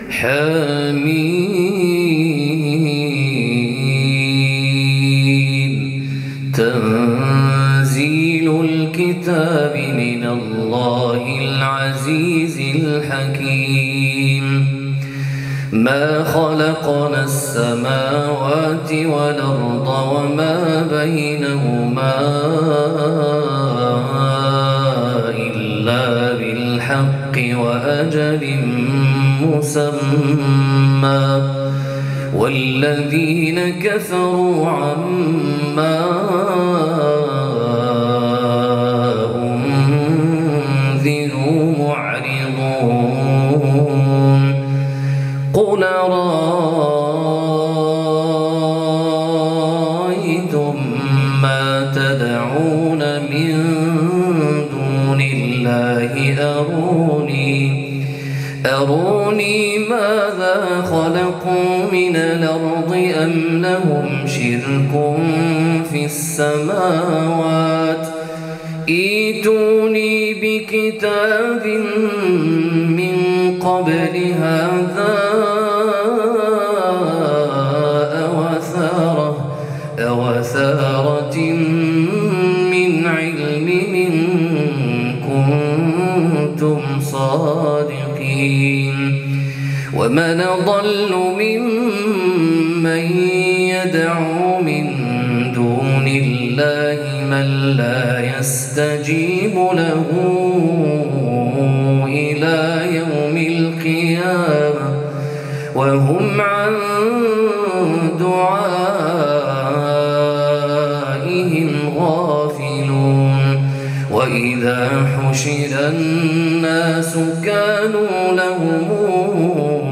فواتح سورة الأحقاف (برواية ورش عن نافع) للقارئ